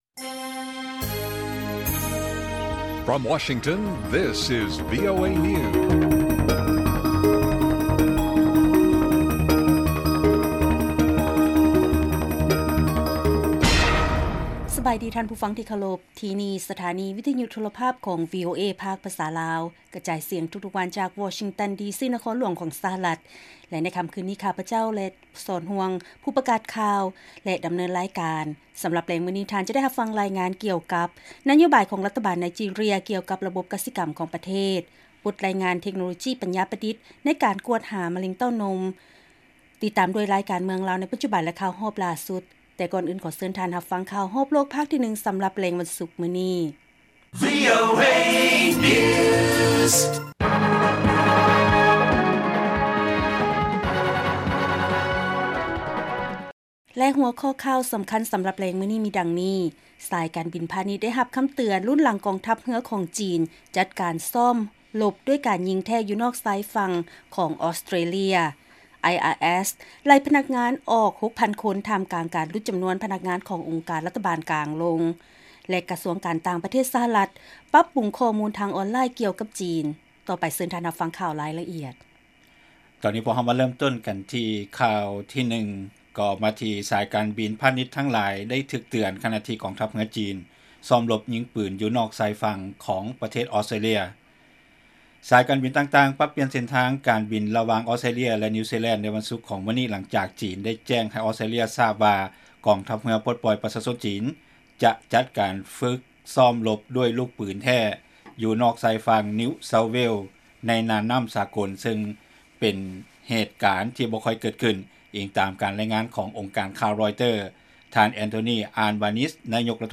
ລາຍການກະຈາຍສຽງຂອງວີໂອເອ ລາວ: ສາຍການບິນພານິດທັງຫຼາຍໄດ້ຖືກເຕືອນ ຂະນະທີ່ກອງທັບເຮືອຈີນ ຊ້ອມລົບຍິງປືນຢູ່ນອກຊາຍຝັ່ງອອສເຕຣເລຍ